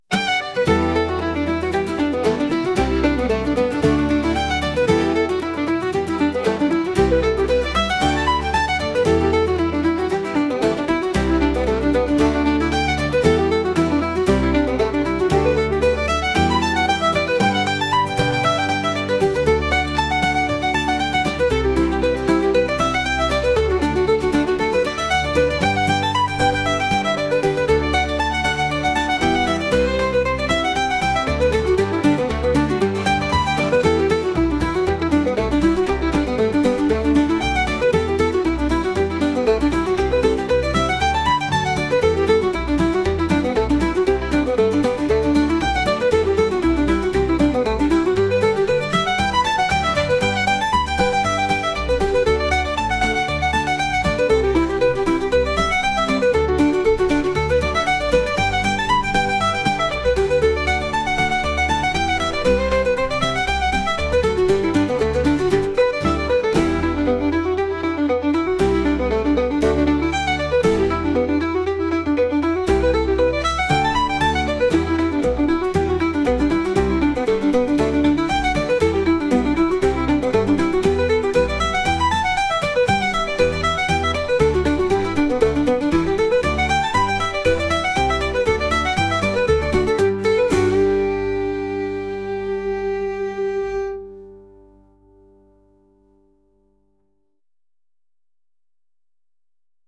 新しい街を訪れたようなケルト音楽です。